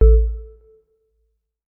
Melodic Power On 6.wav